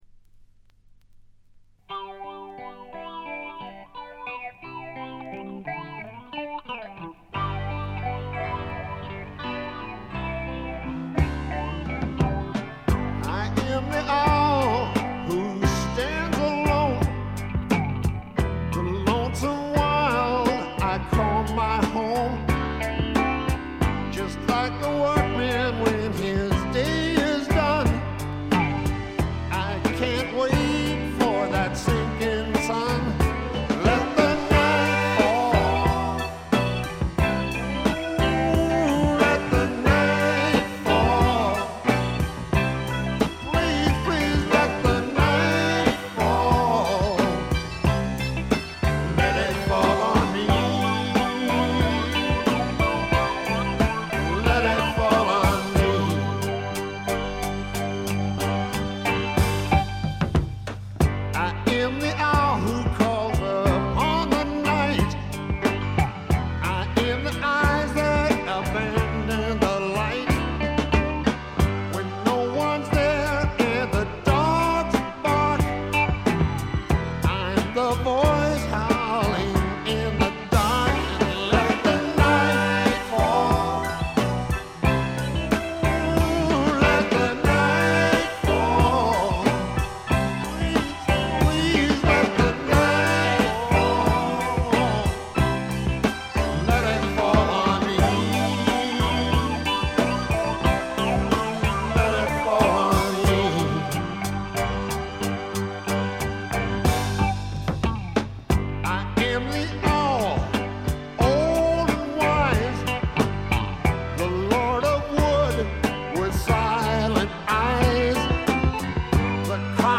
部分試聴ですが、わずかなノイズ感のみ。
試聴曲は現品からの取り込み音源です。